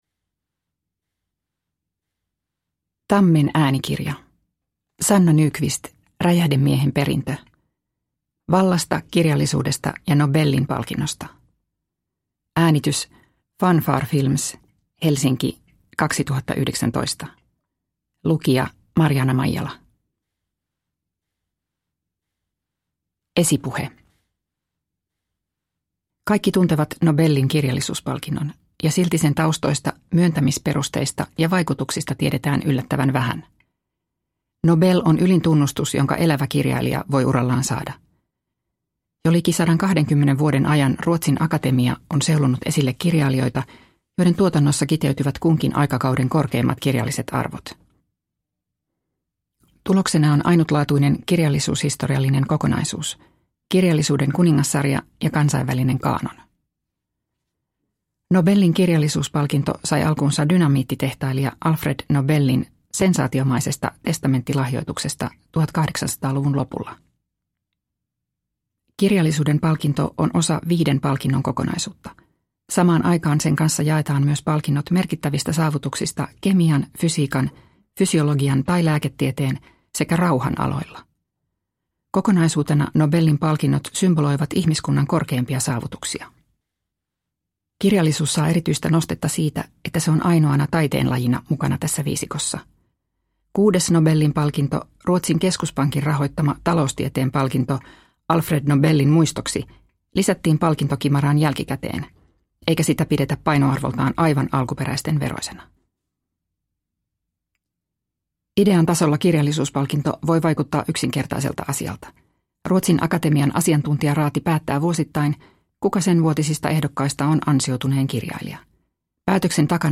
Räjähdemiehen perintö – Ljudbok – Laddas ner